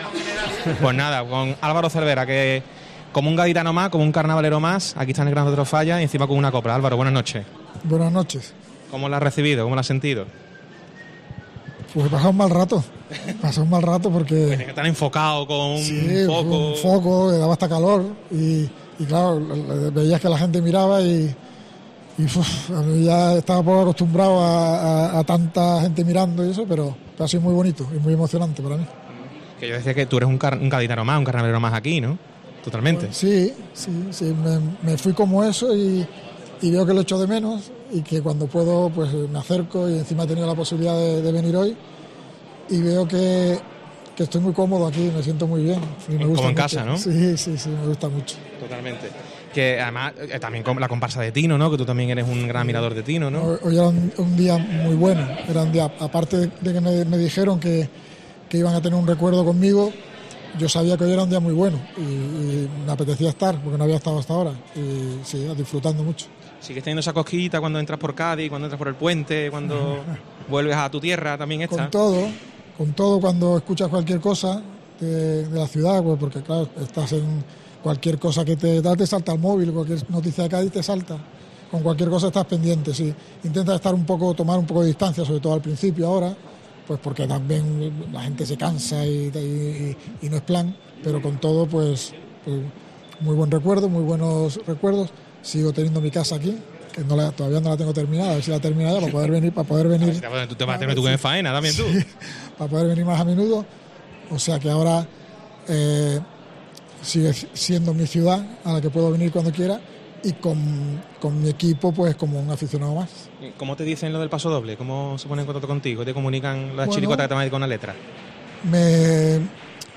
Álvaro Cervera en el micrófono de COPE desde el Falla